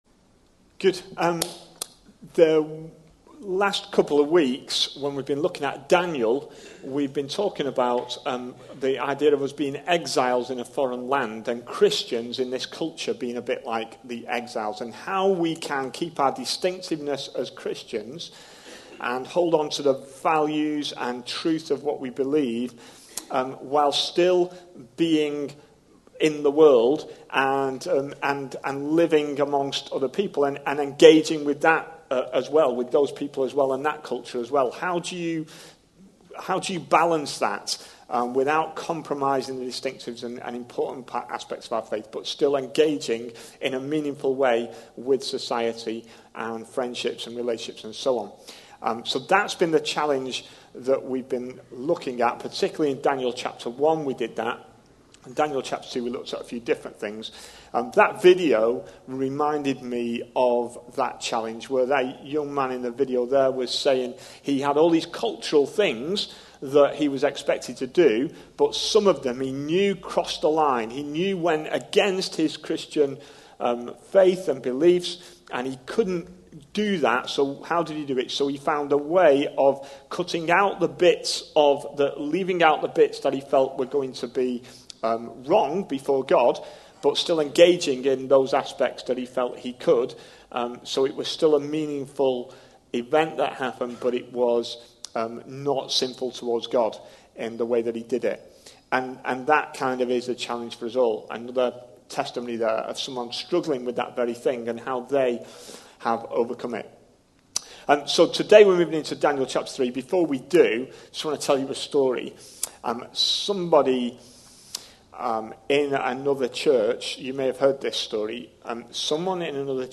A sermon preached on 23rd June, 2019, as part of our Daniel series.